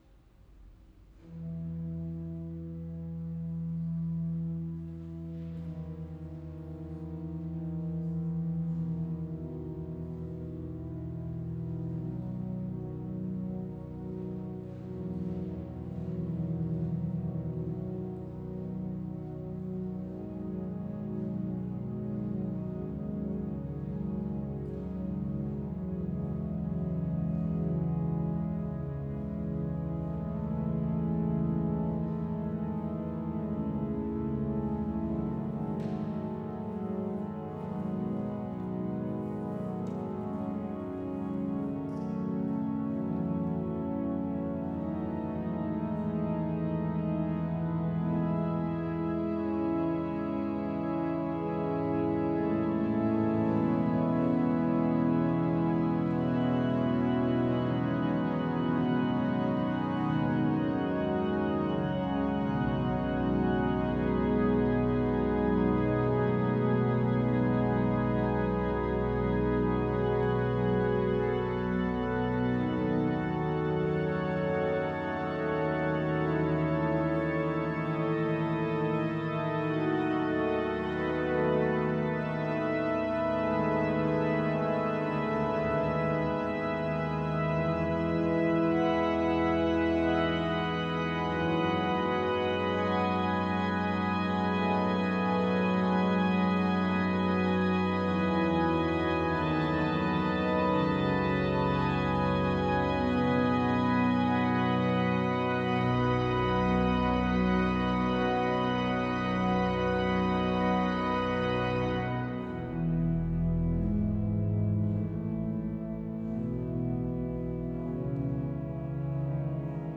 solo for organ